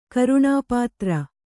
♪ karuṇāpātra